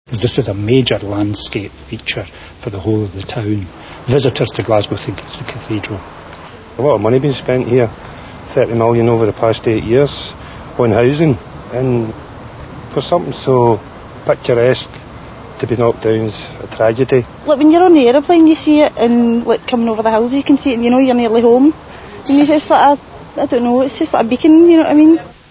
Click the icons to hear sound clips of some local people at the time of the demolition:
voxpops1.mp3